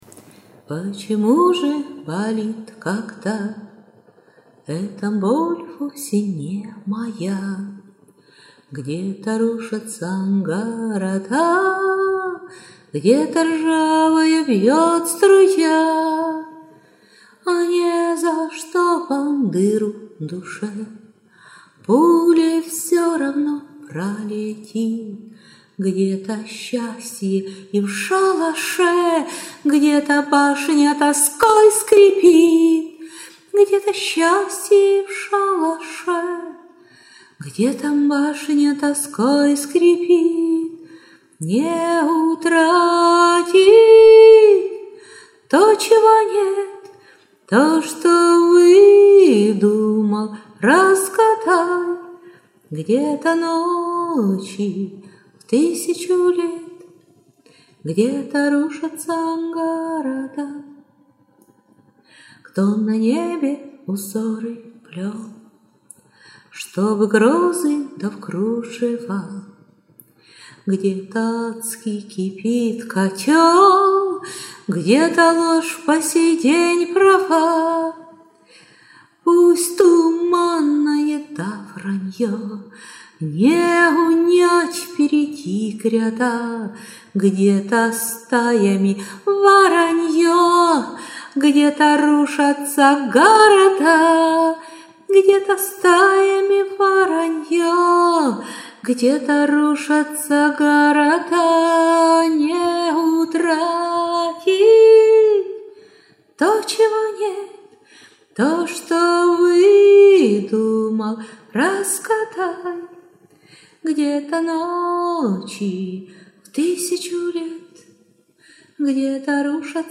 Голос проникає глибоко до душі...